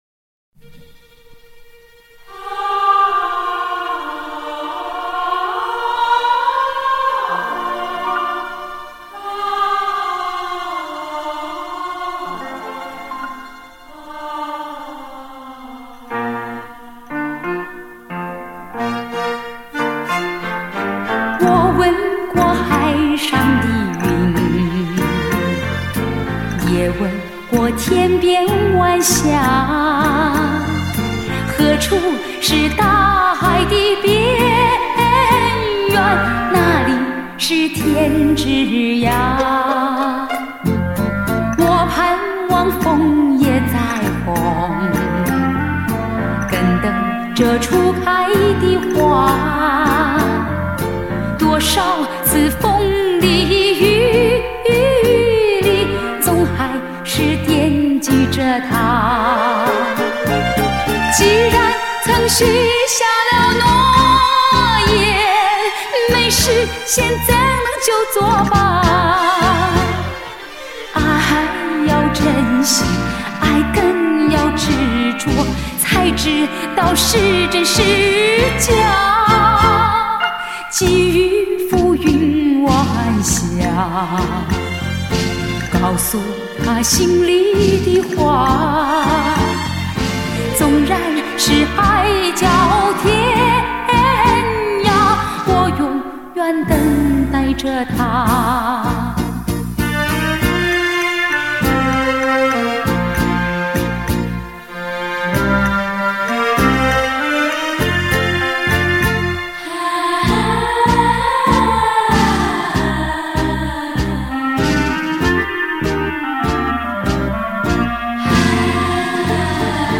她嗓音轻柔亮丽，气质温和优柔。